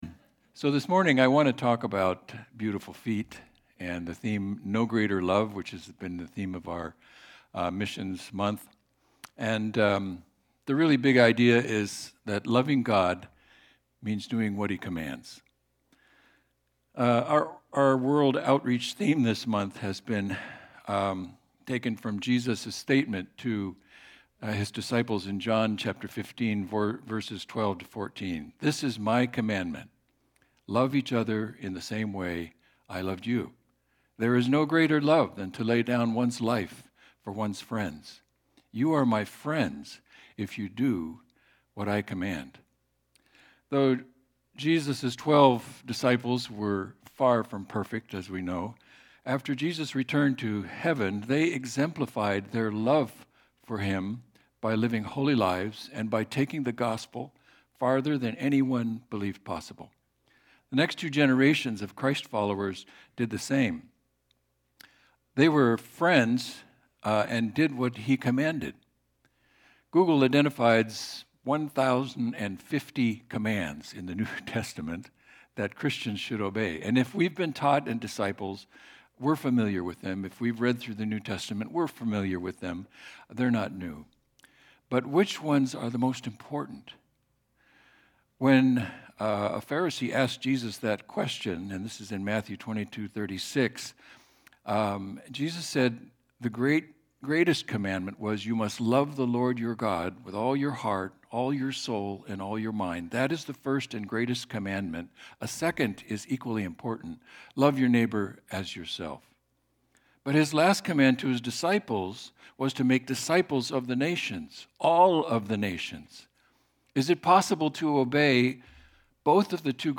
Service Type: Missions